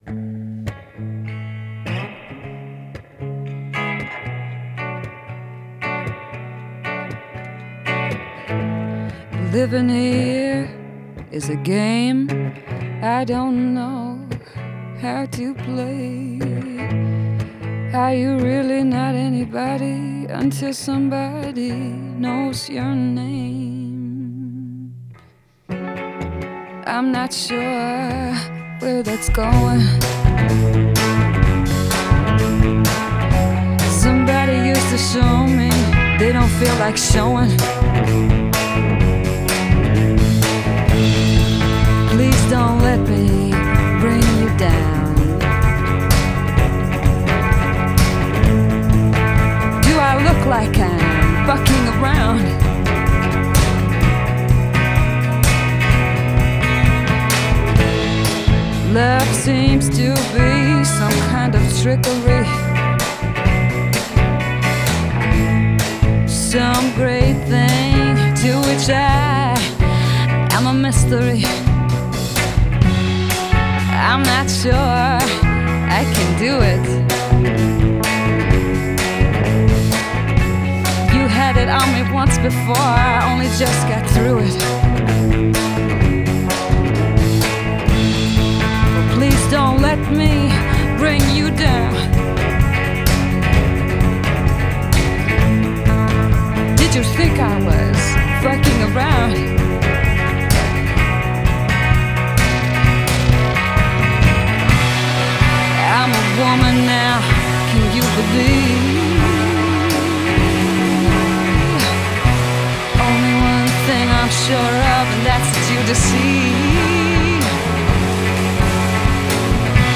Genre: Indie Folk, Alternative